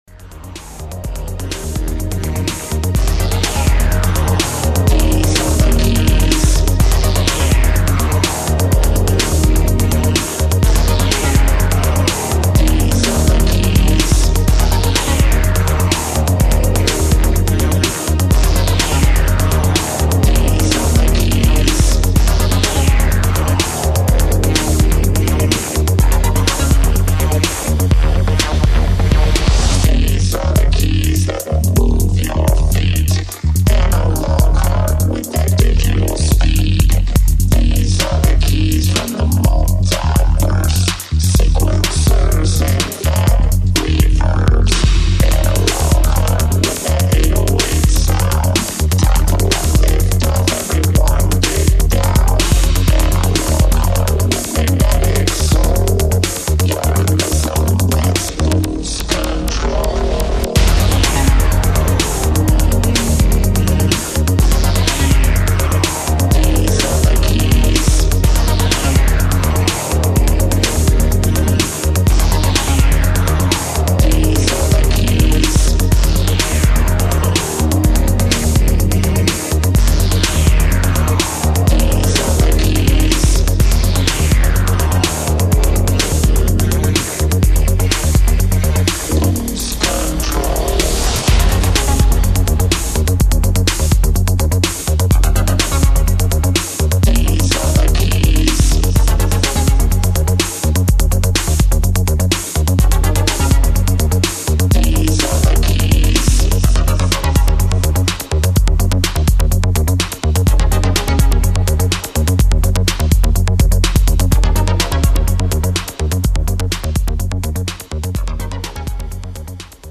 2x electro, 2x house/techno